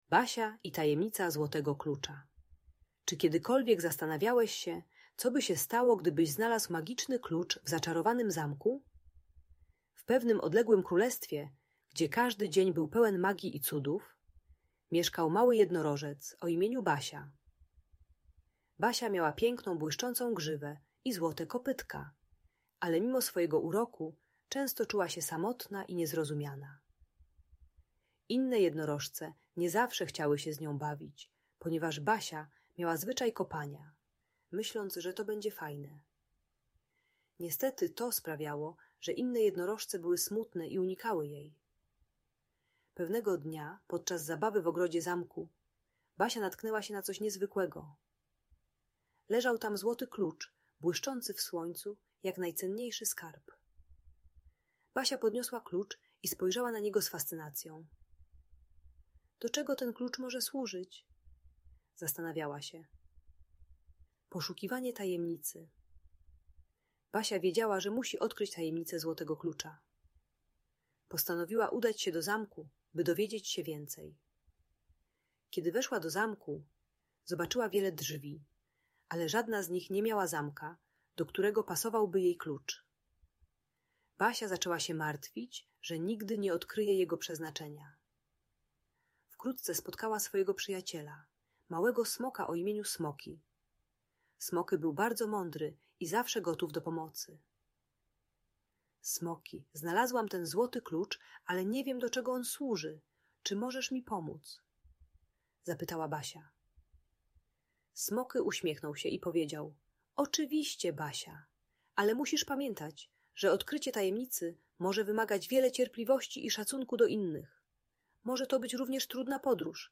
Basia i Tajemnica Złotego Klucza - Bunt i wybuchy złości | Audiobajka
Historia jednorożca Basi uczy, że szacunek do innych otwiera drzwi do prawdziwej przyjaźni. Audiobajka o radzeniu sobie z przemocą rówieśniczą i budowaniu relacji bez kopania.